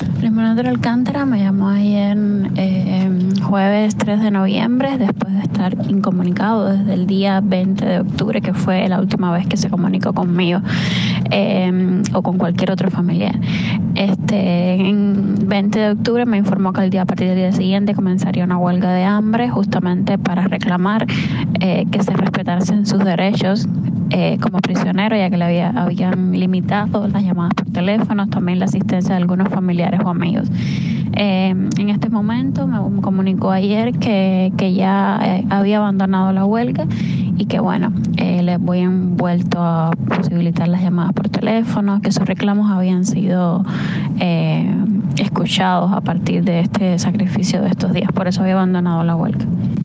Declaraciones de la curadora de arte